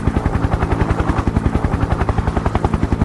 scpcb-godot/SFX/Character/Apache/Propeller.ogg at 34e20b9e84c4340c5663a408ff2cef388eb0407a
Propeller.ogg